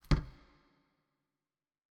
step2_reverb.wav